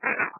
This is the room where we recorded the evp's. This room is almost directly accross from room 410, the haunted Mary Lake Room.
EVP's